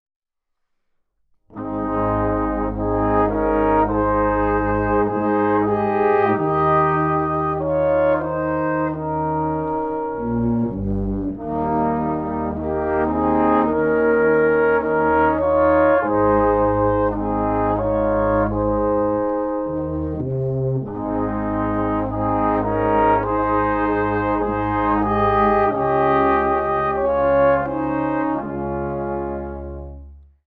Bläsermusik zur Advent- und Weihnachtszeit